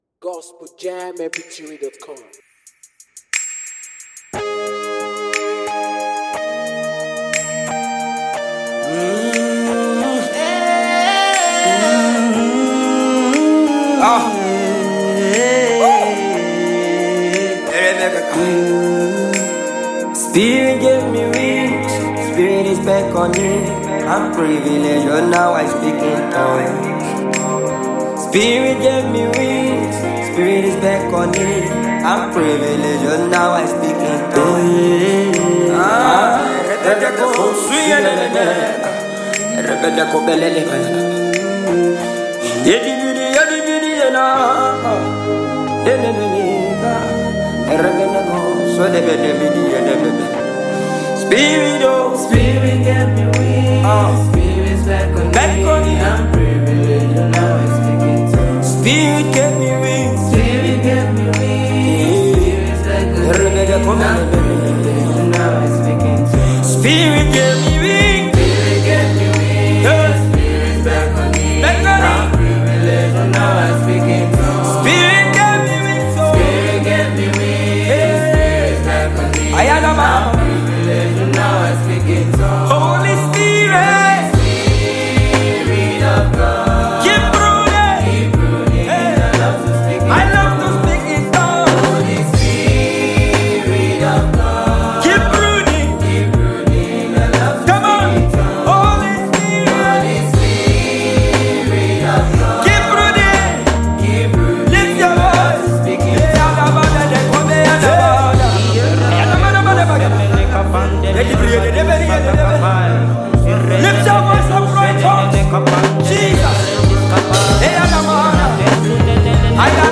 African praiseAfro beatLYRICSmusic
Gospel